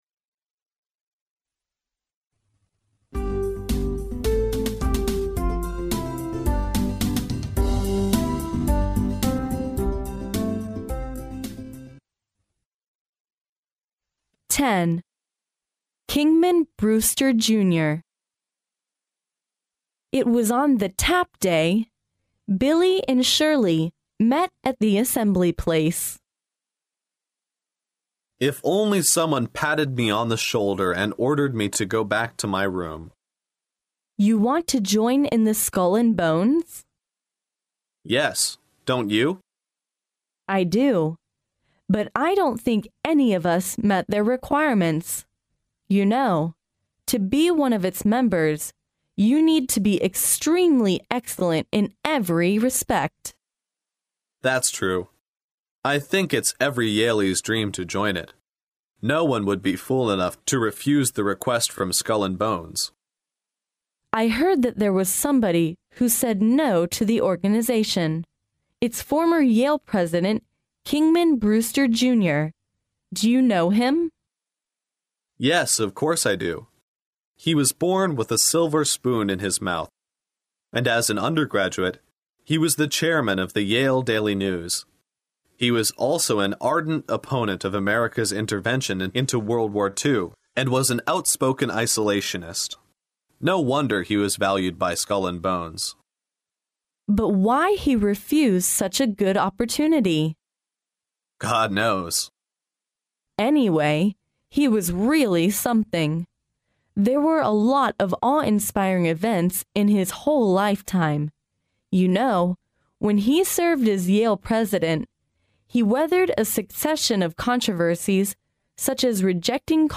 耶鲁大学校园英语情景对话10：不惧威胁的英雄（mp3+中英）